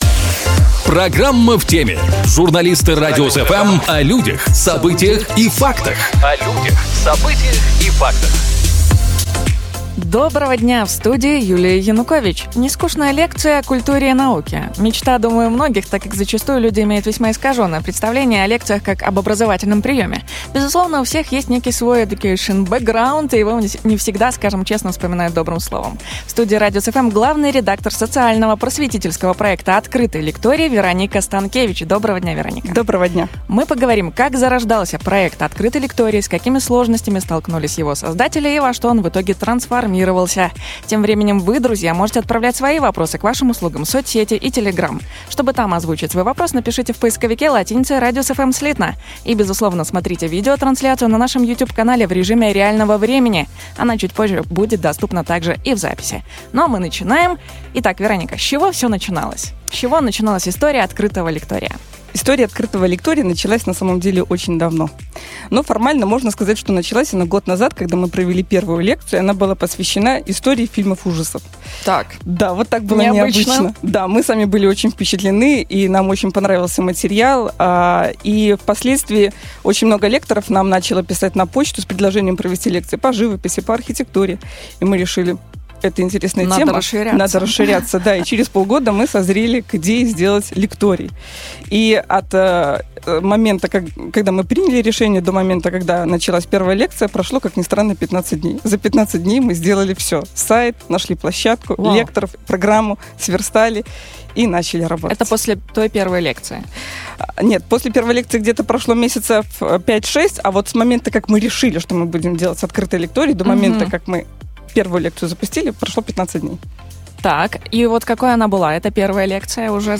В студии "Радиус FМ"